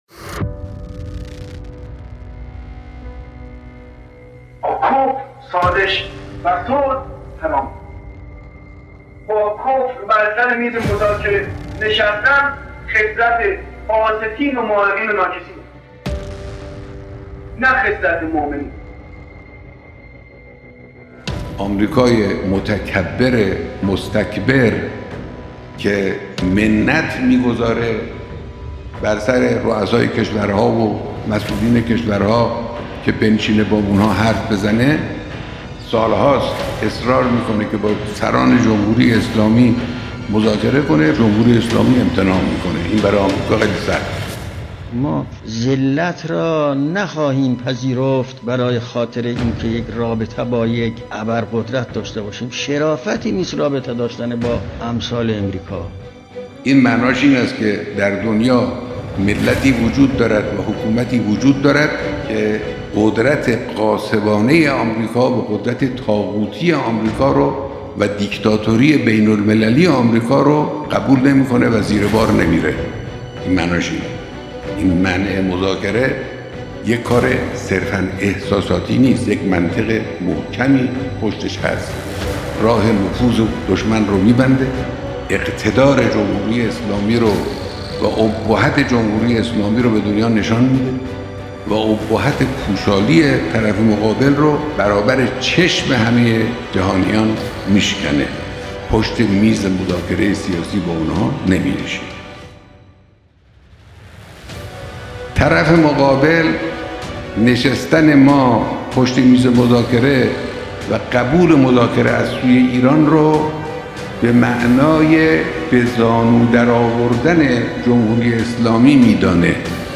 صداهنگ